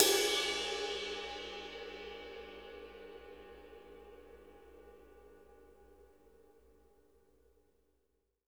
MPBELL    -L.wav